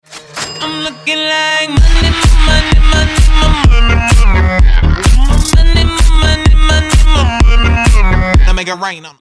Здесь вы найдете забавные, мотивирующие и необычные аудиоэффекты, которые можно использовать при получении донатов.
Уведомление о поступлении средств